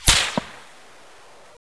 weapon_whizz3.wav